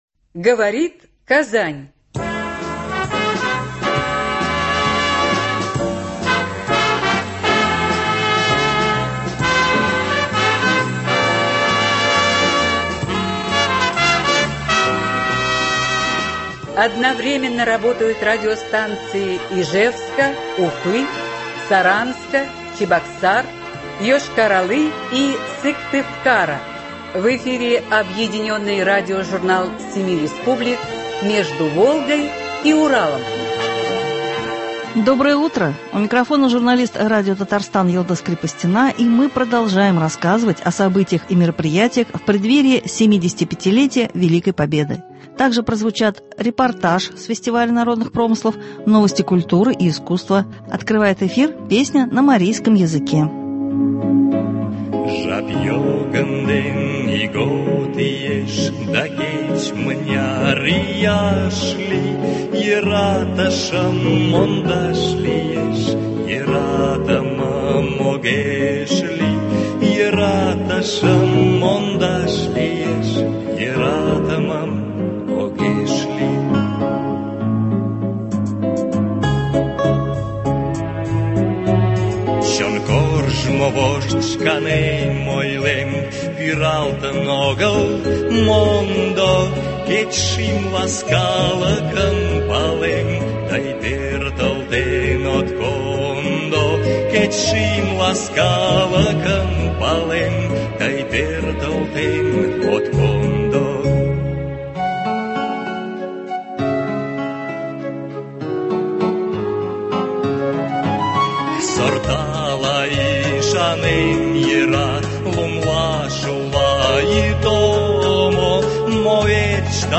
Объединенный радиожурнал семи республик.
Продолжаем рассказывать о событиях и мероприятиях в преддверии 75- летия Великой Победы. Также прозвучат репортаж с фестиваля народных промыслов, новости культуры и искусства.